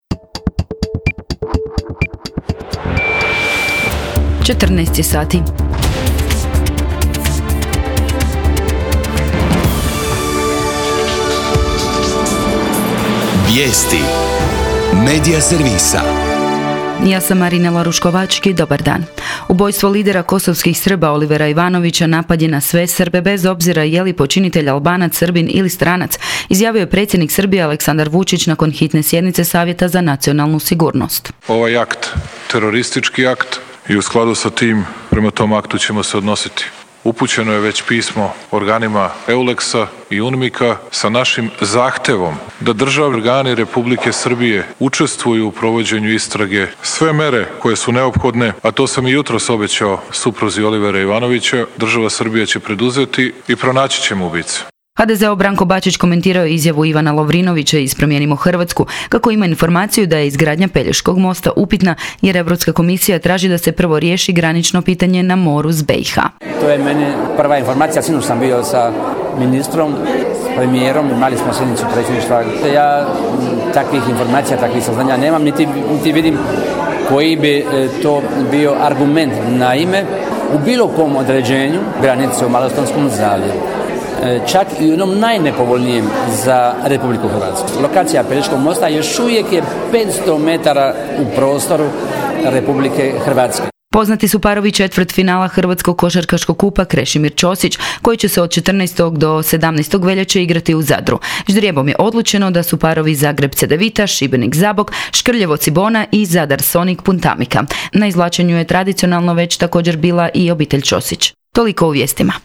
VIJESTI U 14